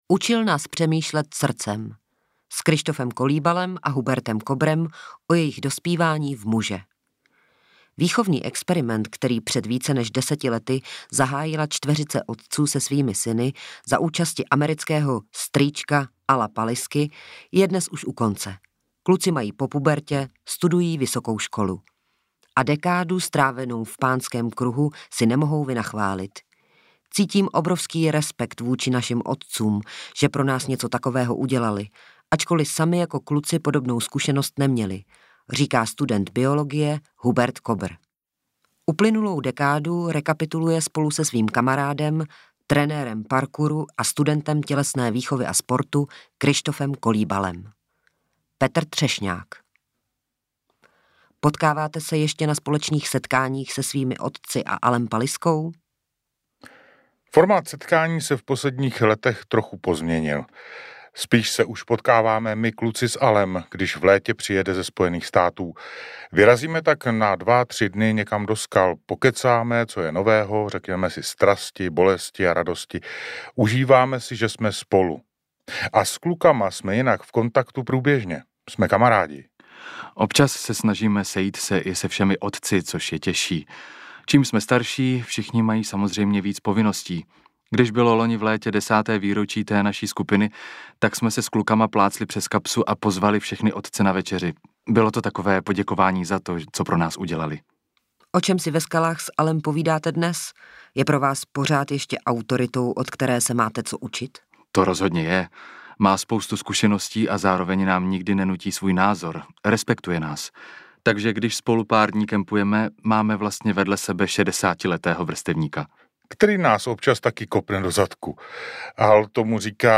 Nahráno ve společnosti 5Guests.